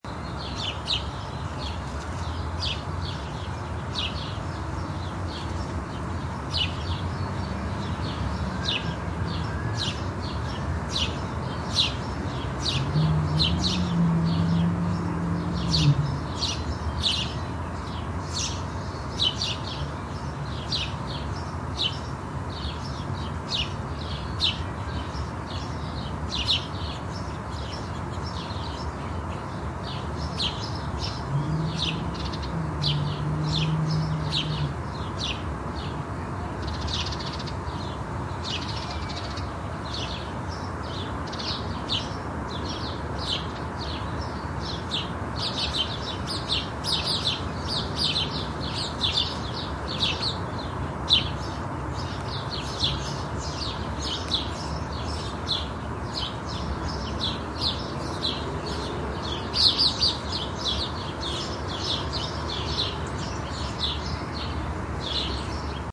Download Park sound effect for free.
Park